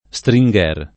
vai all'elenco alfabetico delle voci ingrandisci il carattere 100% rimpicciolisci il carattere stampa invia tramite posta elettronica codividi su Facebook Stringher [ S tri jg$ r ] cogn. — ma S tr &jg er il finanziere Bonaldo S. (1854-1930)